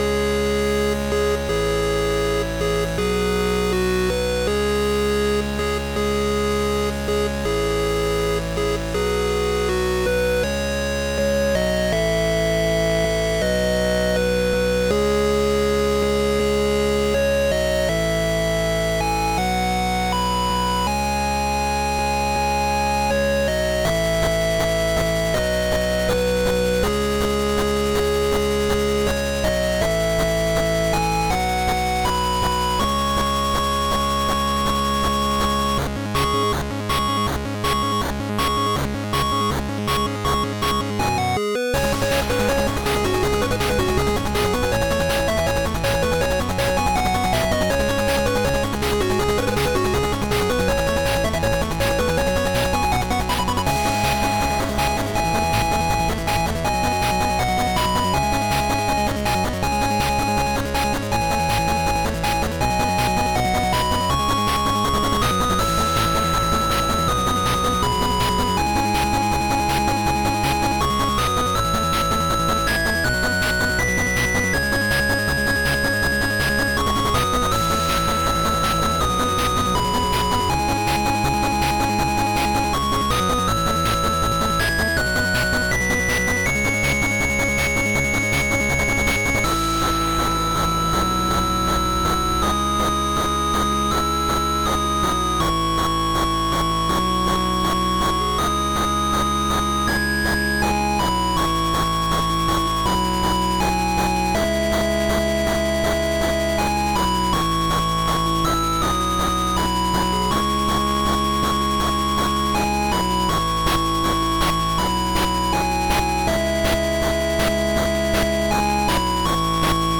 Chiptune remix